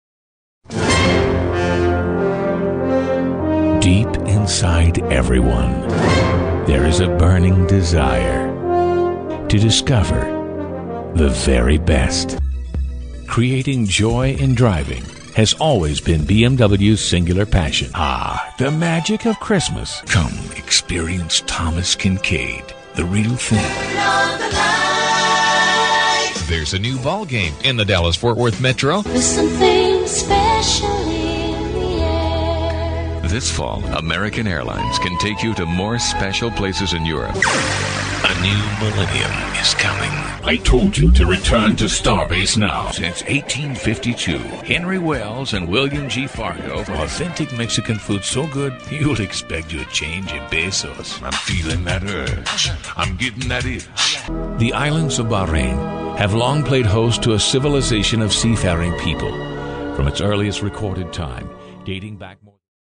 englischsprachiger Profi-Sprecher fuer Werbung, Radio, TV, ...
Kein Dialekt
Sprechprobe: Werbung (Muttersprache):